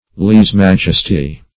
Search Result for " lese-majesty" : The Collaborative International Dictionary of English v.0.48: Lese-majesty \Lese`-maj"es*ty\ (l[=e]z`-m[a^]j"[e^]s*t[y^]), n. See Leze majesty .
lese-majesty.mp3